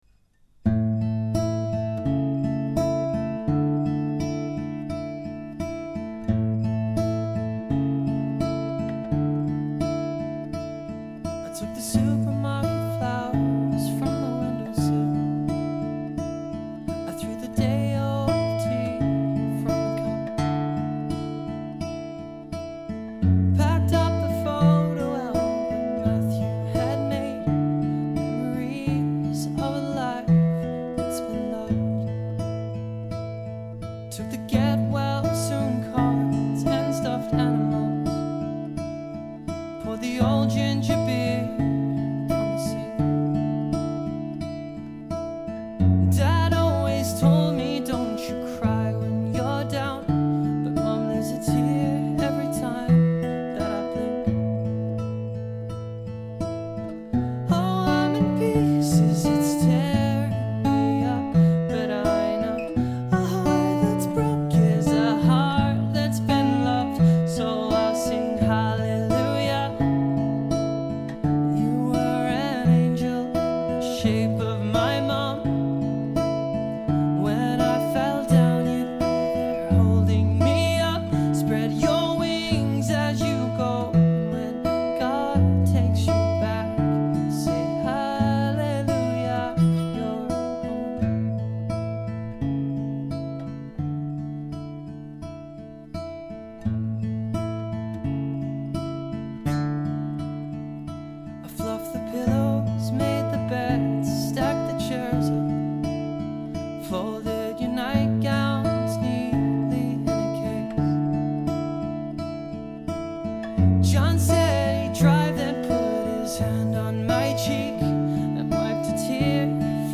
Audio Clip from the Tutorial
Capo 2nd Fret - 4/4 Time